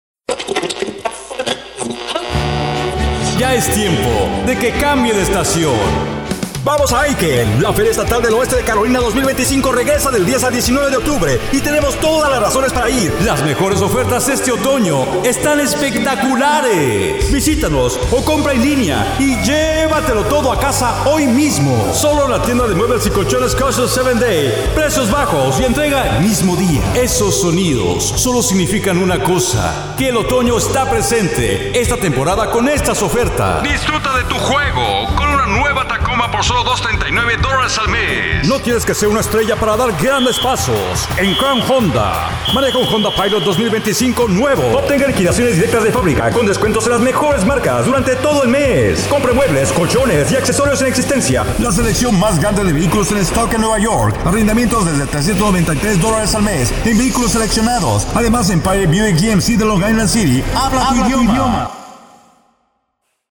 I'm a mid West guy who's been described as having a warm, caring guy next store voice and with a sense of humor.
Friendly and conversational....
English (North American) Adult (30-50) | Older Sound (50+)